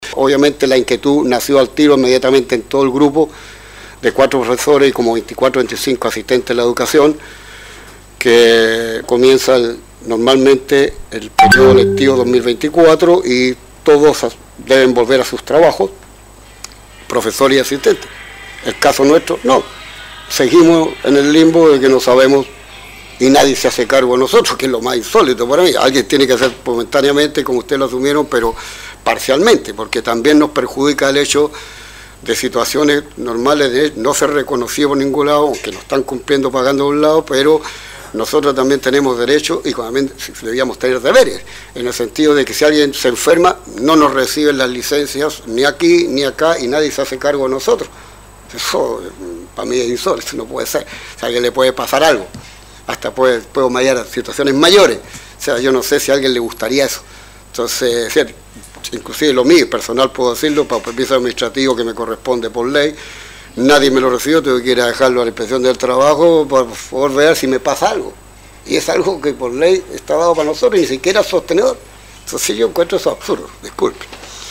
ante el concejo municipal, en una reunión ordinaria que tuvo lugar la semana pasada